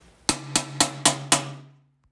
Banging On Metal.m4a
fbi Hit Impact Metal multiple stick sound effect free sound royalty free Memes